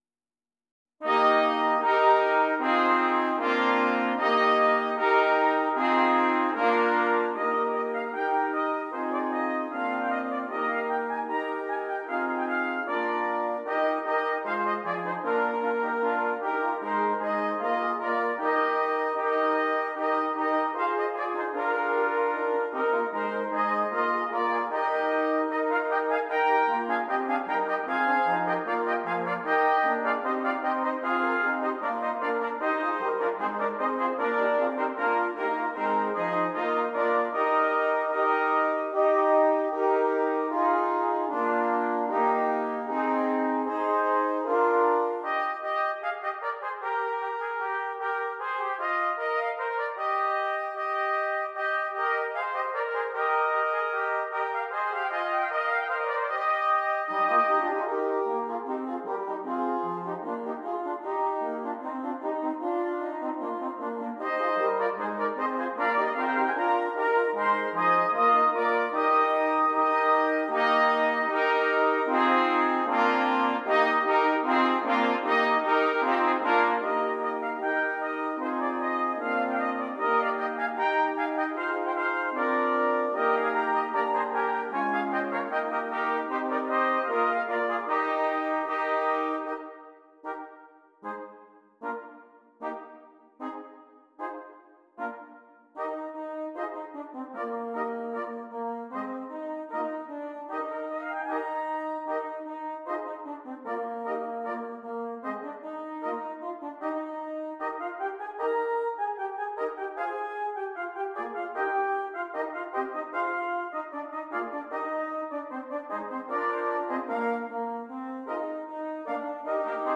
6 Trumpets
Traditional Carol